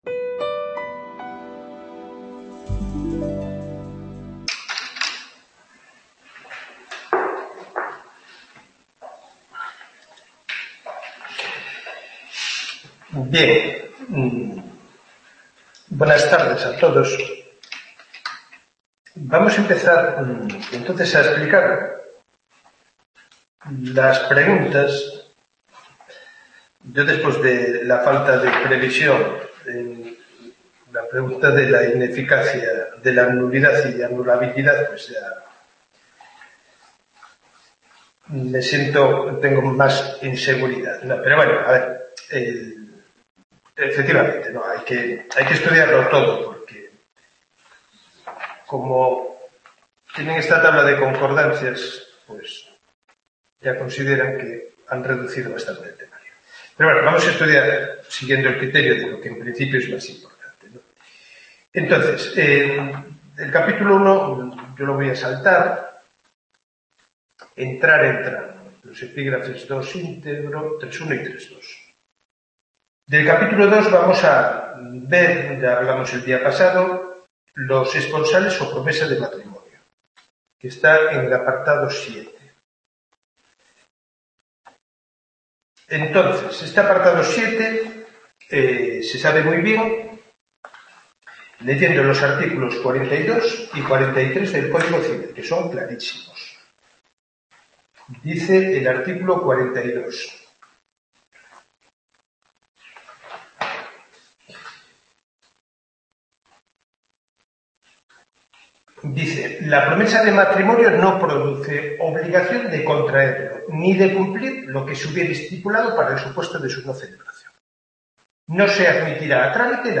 Tutoría 26-02-21